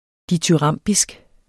Udtale [ dityˈʁɑmˀbisg ]